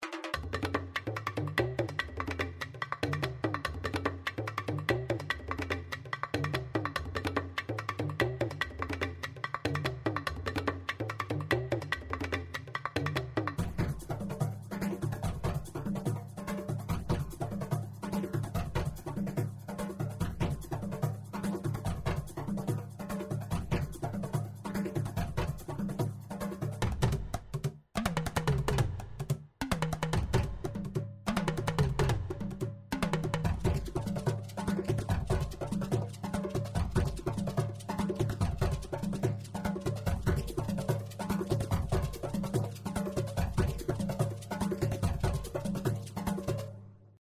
アフリカリズム